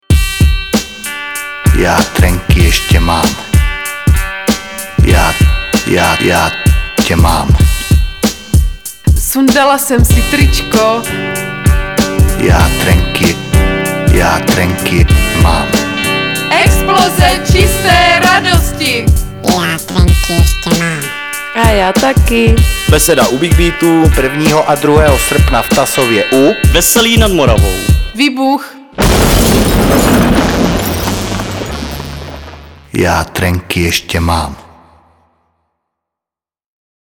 Reklama na letošní Besedu u Bigbítu natočena. Dokonce ve dvou verzích (dalo by se tak říct).
Soubory ke stažení BuB 2008 radio edition (744.31 kB) Oficiální reklama BuB 2008 home edition (888.92 kB) Neoficiální reklama